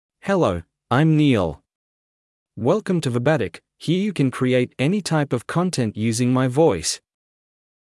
MaleEnglish (Australia)
Neil is a male AI voice for English (Australia).
Voice sample
Listen to Neil's male English voice.
Neil delivers clear pronunciation with authentic Australia English intonation, making your content sound professionally produced.